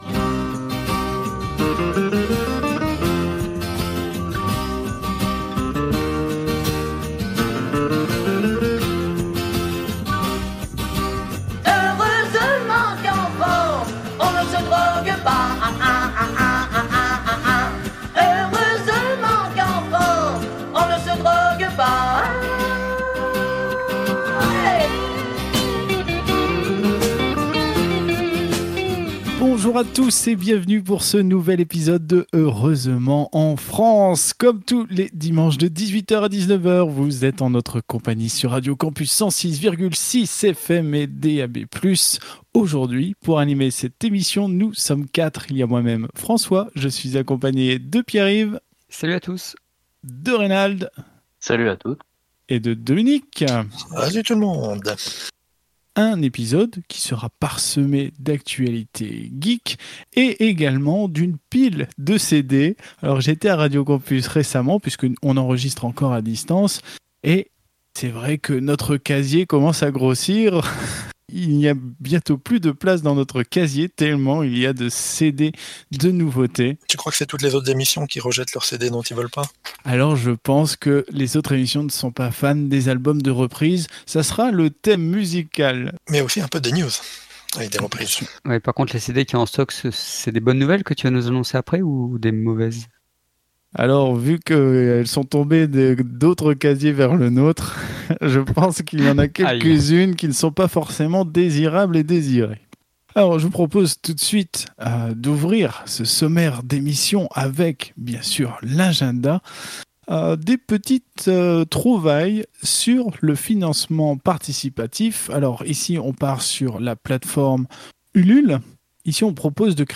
Au sommaire de cet épisode diffusé le 16 mai 2021 sur Radio Campus 106.6 :